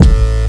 GUnit Synth.wav